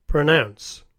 The verb pronounce has the vowel of MOUTH, as in noun:
pronounce.mp3